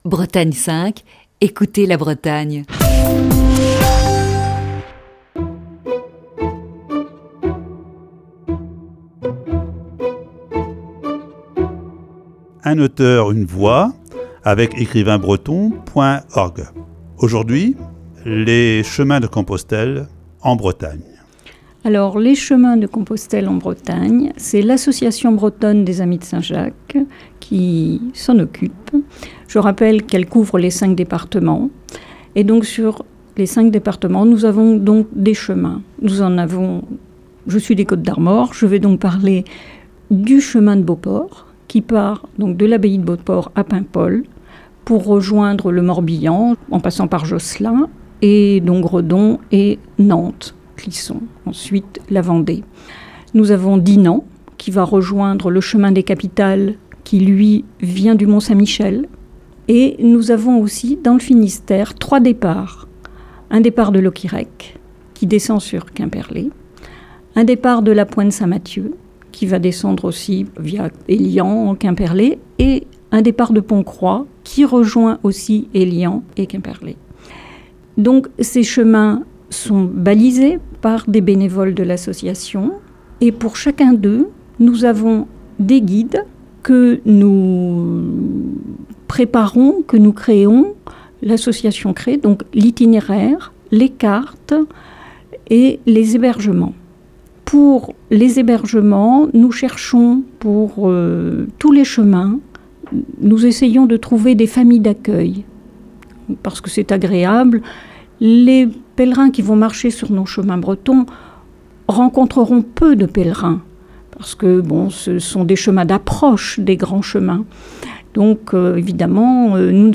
Rediffusion